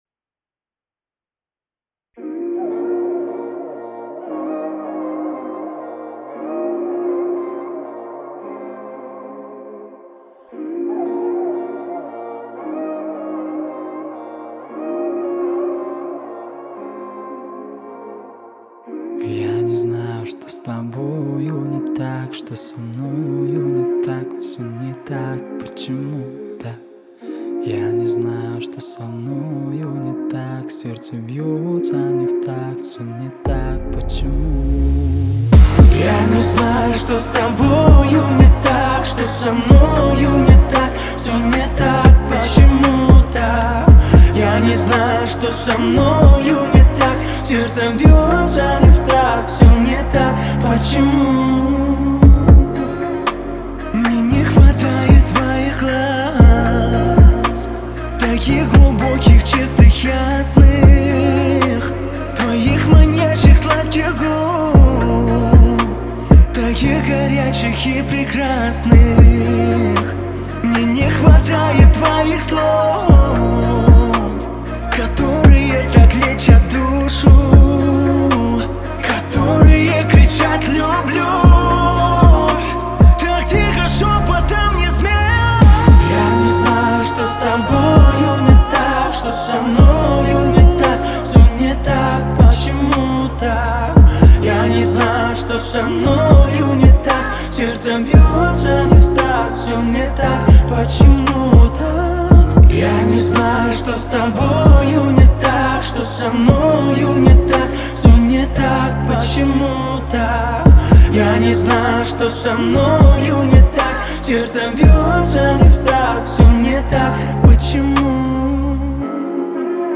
поп-рэп